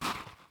sfx_foot_sand_light.wav